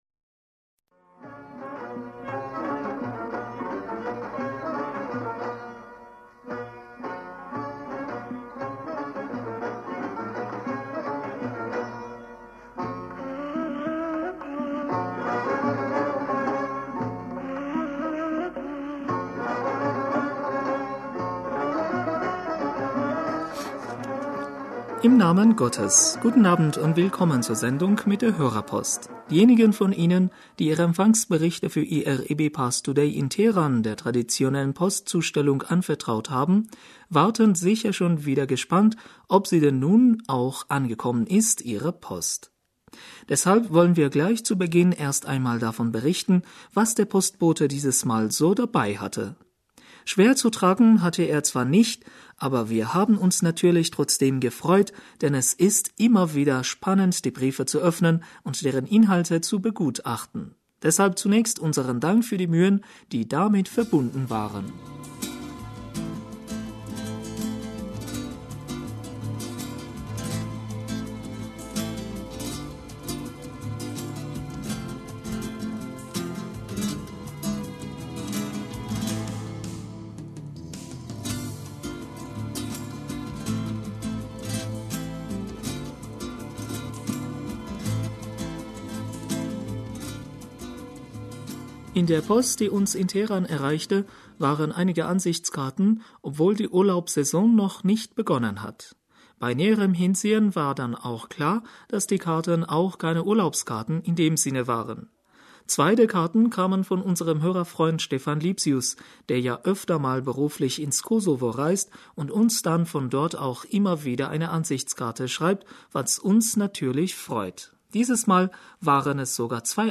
Hörerpostsendung am 27. Mai 2018 - Bismillaher rahmaner rahim - Guten Abend und willkommen zur Sendung mit der Hörerpost.